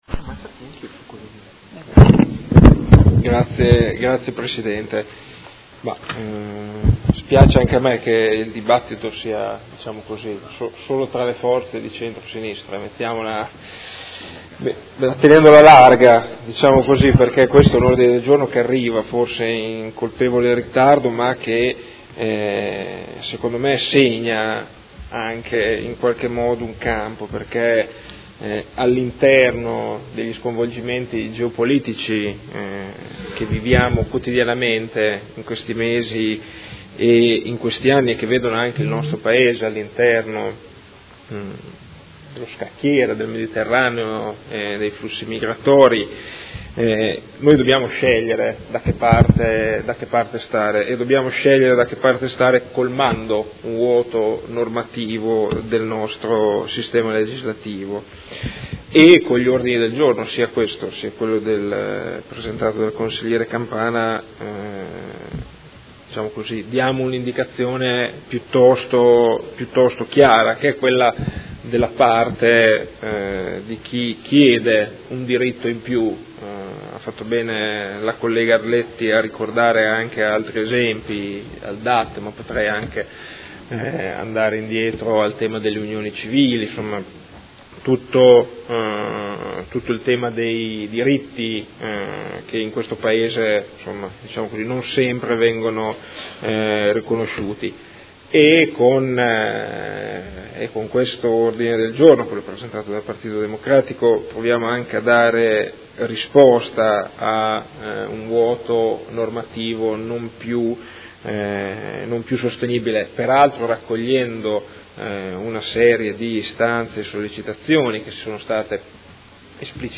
Seduta del 20/04/2017.
Audio Consiglio Comunale